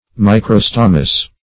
Microstomus - definition of Microstomus - synonyms, pronunciation, spelling from Free Dictionary